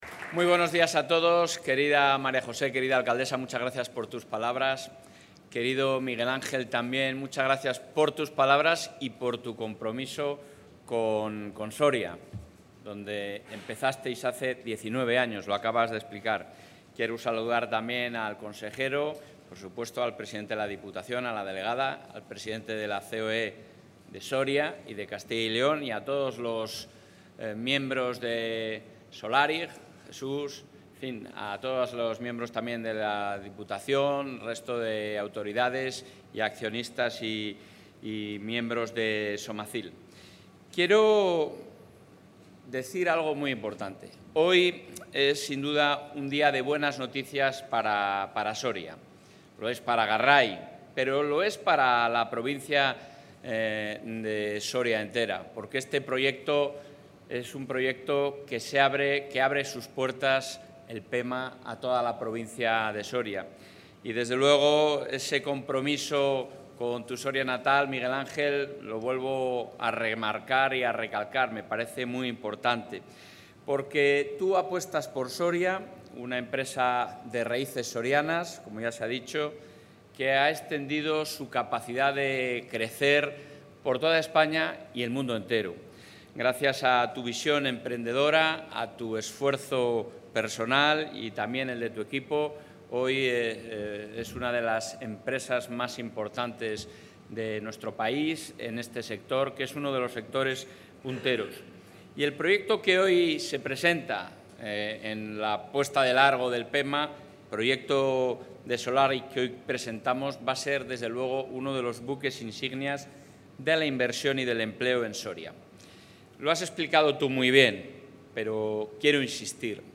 Intervención del presidente de la Junta.
El presidente de la Junta de Castilla y León, Alfonso Fernández Mañueco, ha participado en el acto de presentación del proyecto de la nueva planta de producción de combustible sostenible de Solarig Global Service SA en Garray, Soria, que ha sido posible gracias al apoyo del Gobierno autonómico.